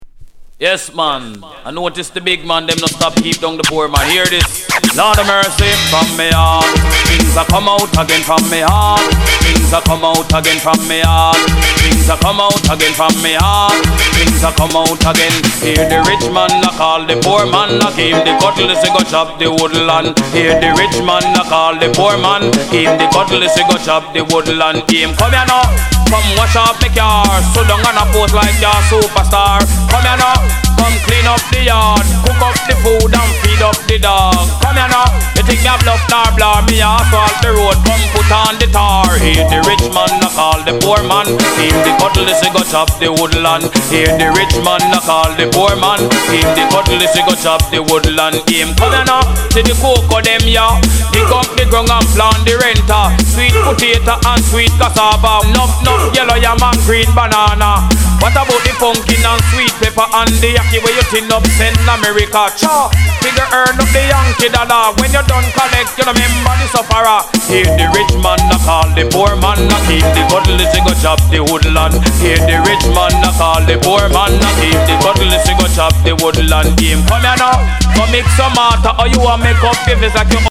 Genre: Reggae/Dancehall